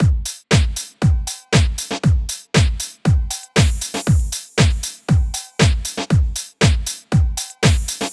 12 Drumloop.wav